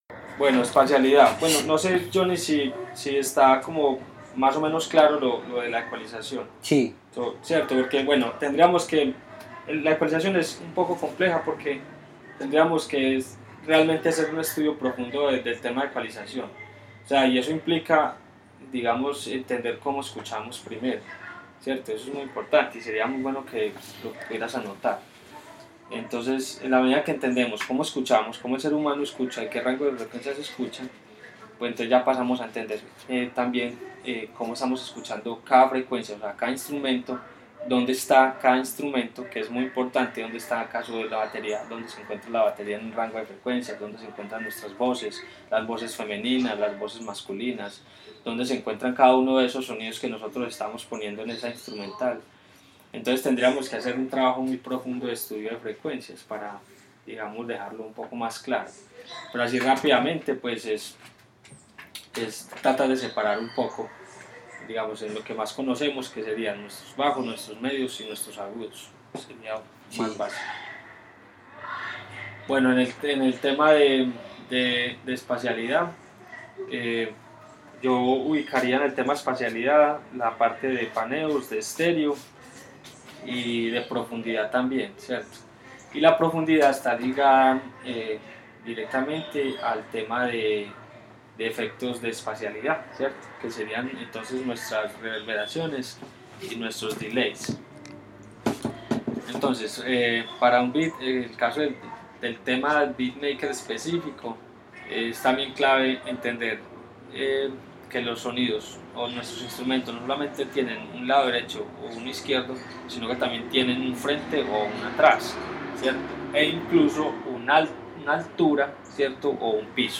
entrevista lupa espacialidad